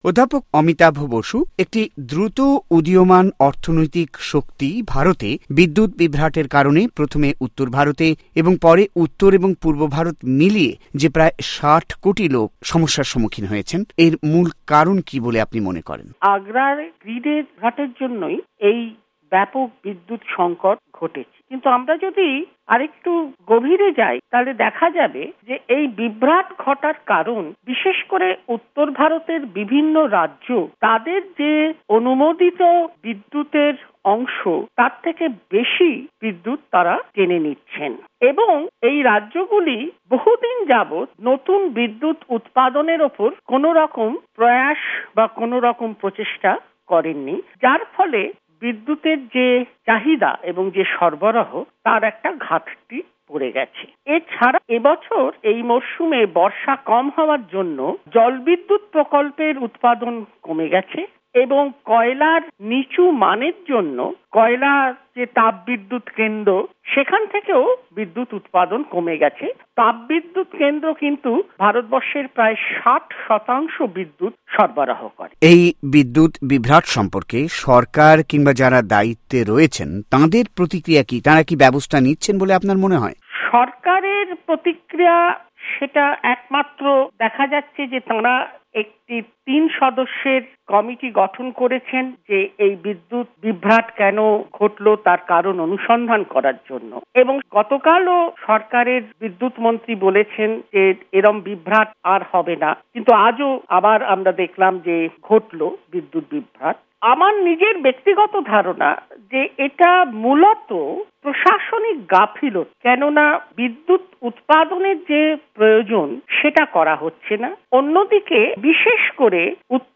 সাক্ষাতকার